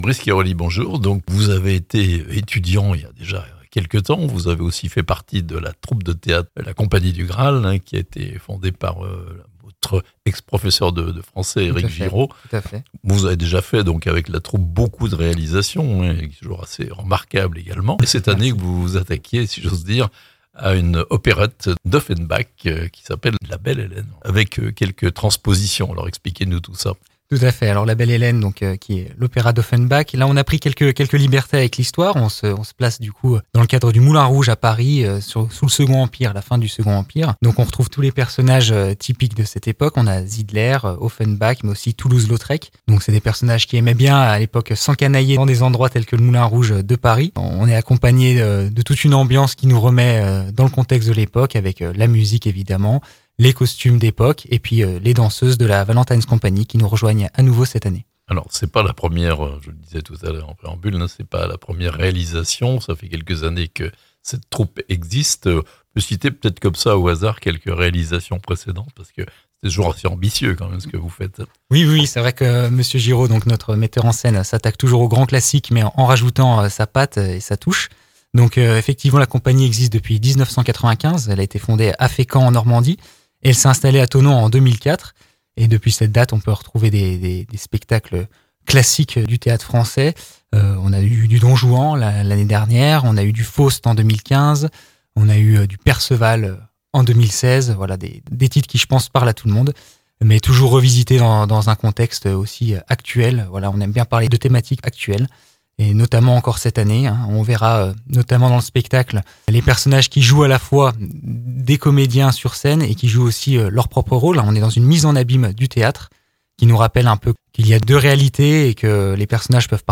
La Belle Hélène, l'opérette d'Offenbach au Moulin Rouge mais à Thonon (interview)